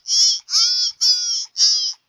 AV_deer_long.wav